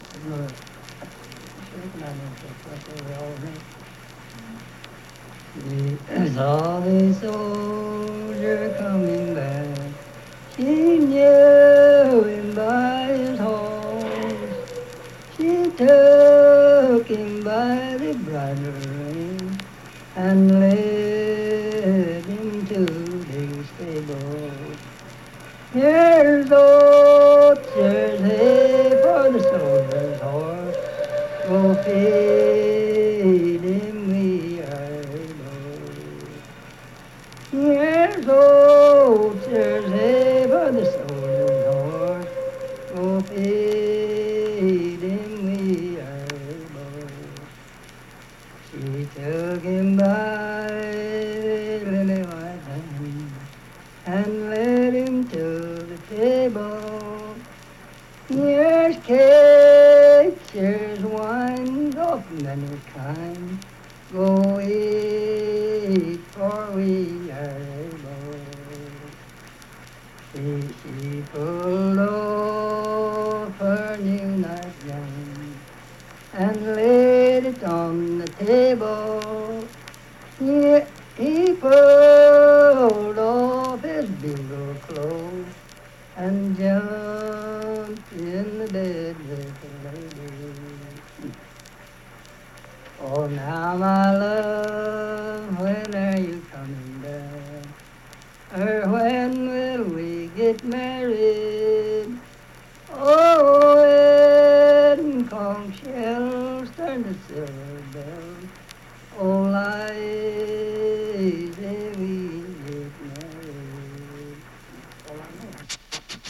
Unaccompanied vocal music
Verse-refrain, 5(4).
Voice (sung)
Lincoln County (W. Va.), Harts (W. Va.)